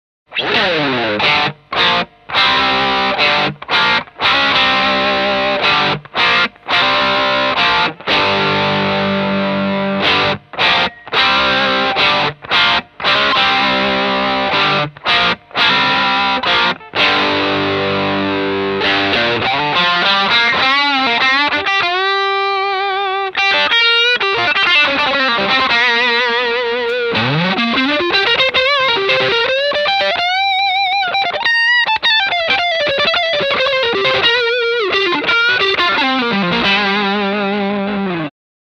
Vintage Fuzz and Distortion Blender - Bold Distortion
- Vintage Fuzz and Traditional Distortion
Demo with Single Pickup 1